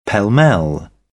pell-mell_UK.mp3